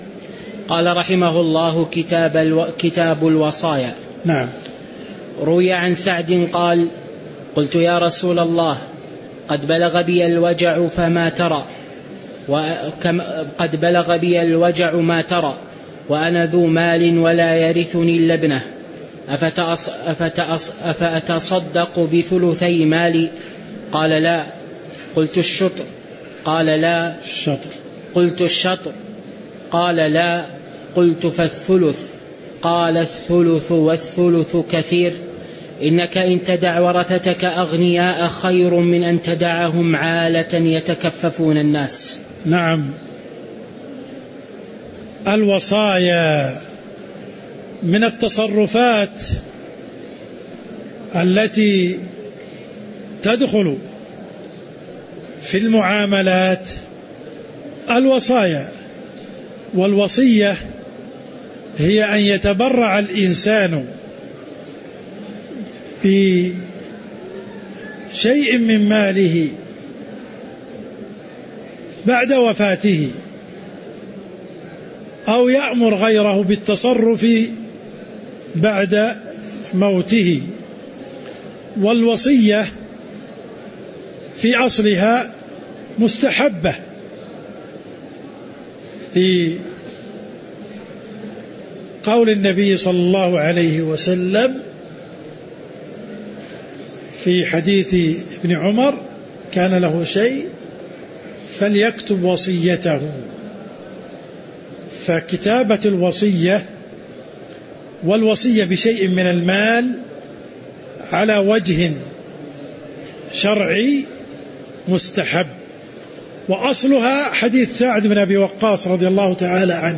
تاريخ النشر ١٨ ربيع الأول ١٤٤٤ هـ المكان: المسجد النبوي الشيخ: عبدالرحمن السند عبدالرحمن السند قوله: كتاب الوصايا (01)